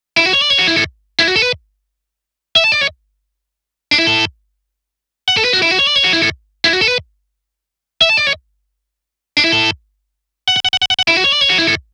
その他素材(エレキギター)試聴
Neve 88RLB使用後